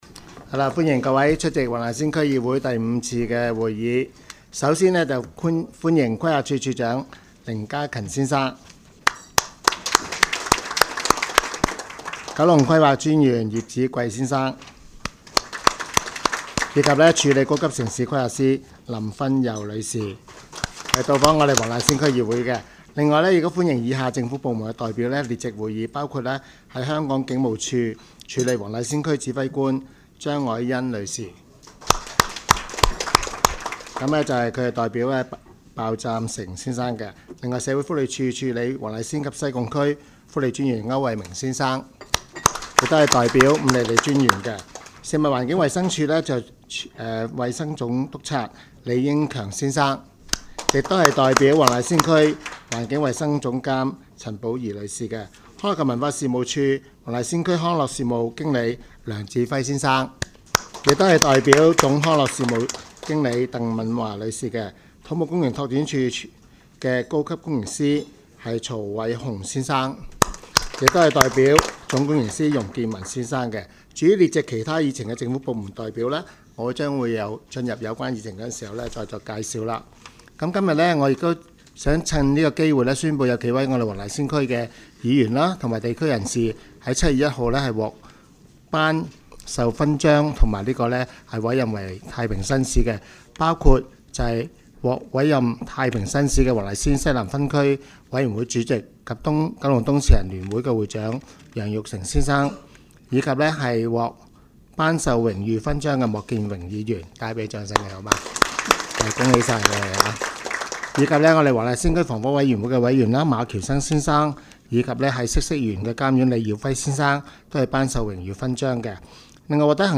区议会大会的录音记录
黄大仙区议会第五次会议